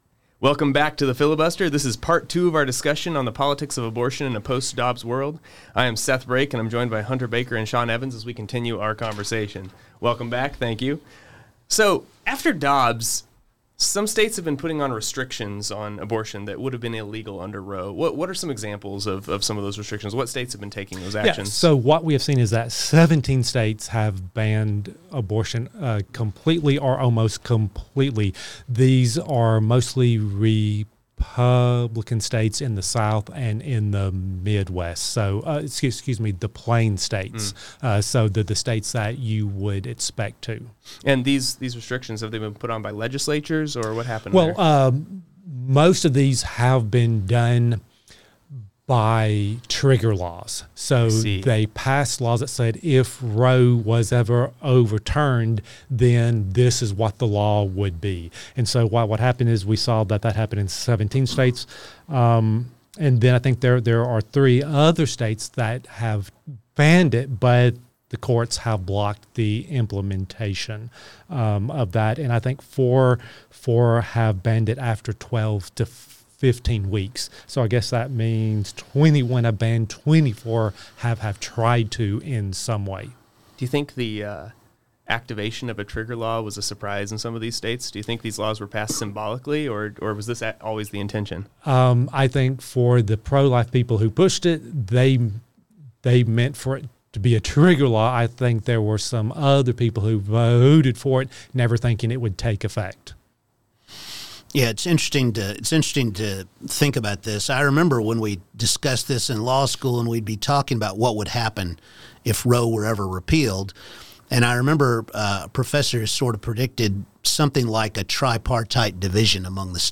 The faculty discuss the different strategies of the Republican and Democratic parties in light of these changes and what the future of the pro-life movement looks like.